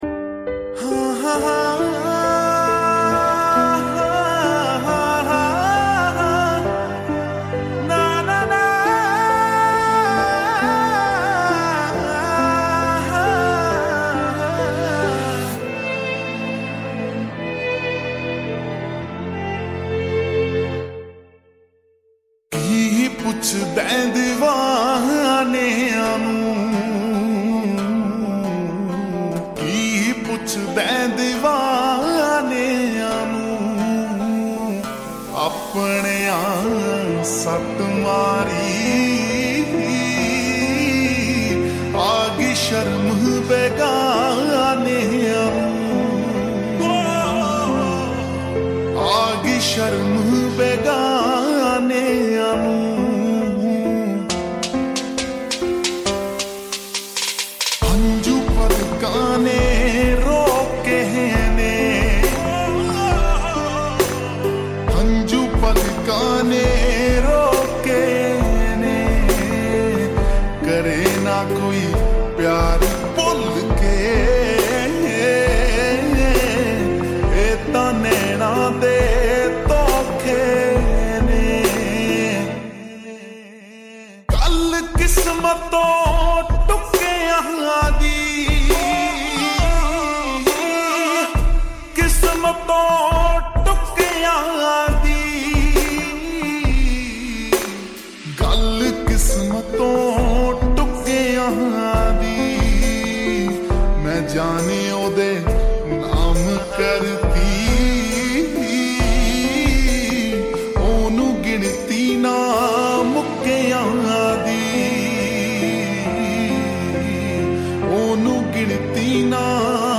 Slowed And Reverb
Sad song